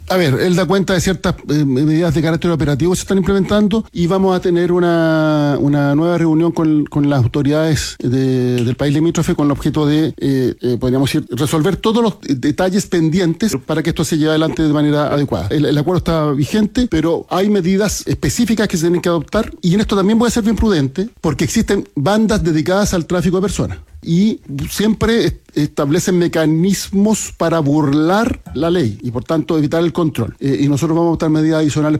Durante una entrevista, el secretario de Estado reconoció que aún hay medidas operativas por resolver y anunció una nueva reunión con las autoridades bolivianas para abordar los detalles pendientes.